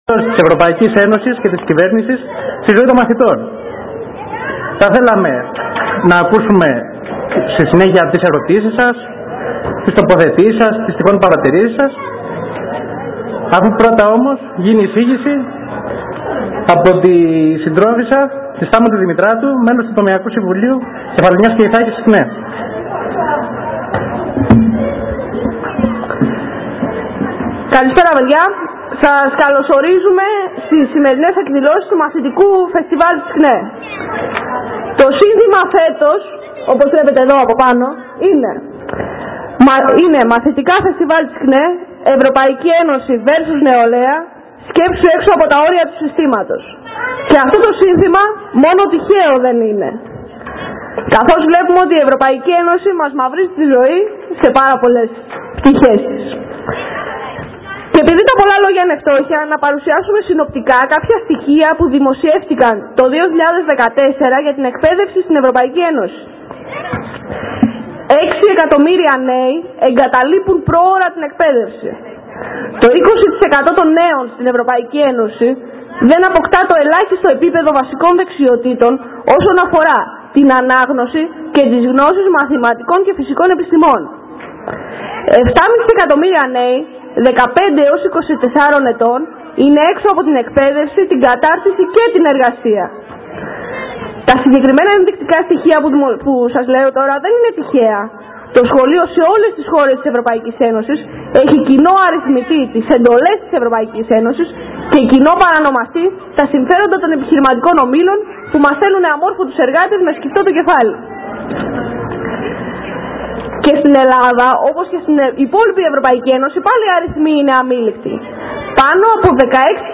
ΣΥΖΗΤΗΣΗ-ΚΝΕ.mp3